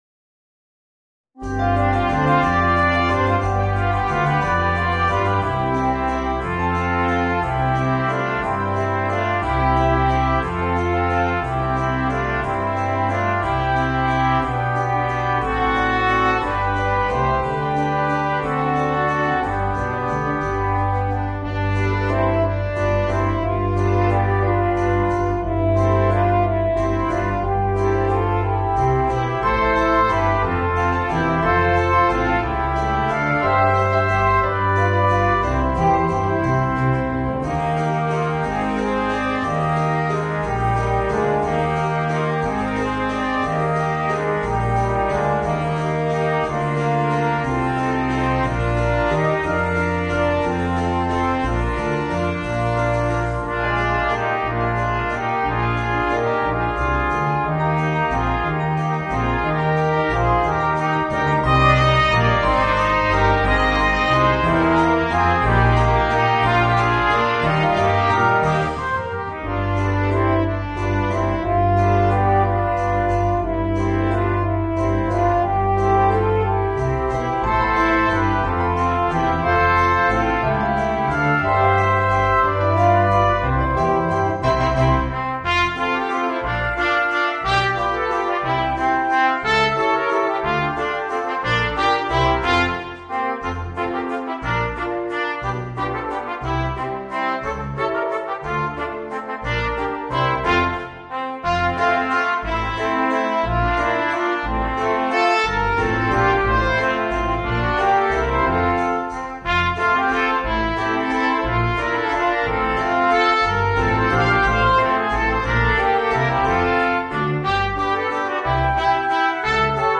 Voicing: 2 Trumpets, 2 Trombones and Piano